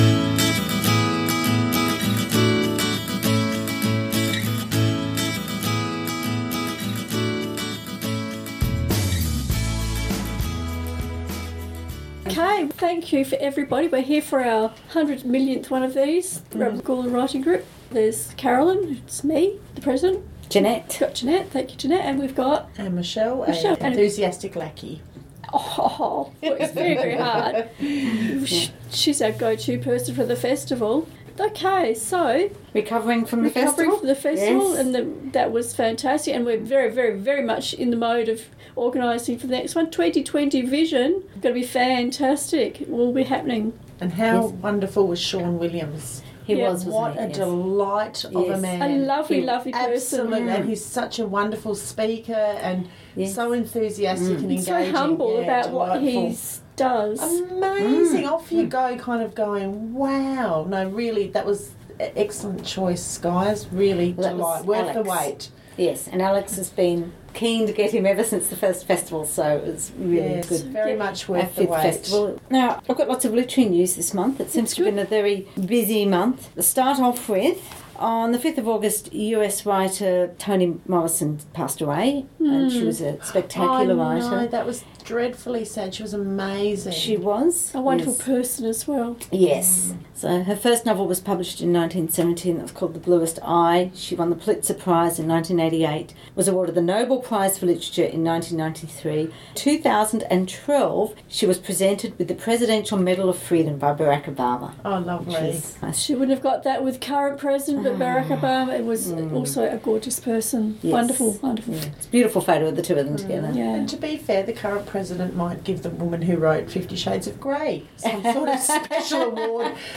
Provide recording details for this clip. Adelaide Plains Chapter and Verse monthly podcast recorded Thursday 19th September at the Studio, Community House, Gawler.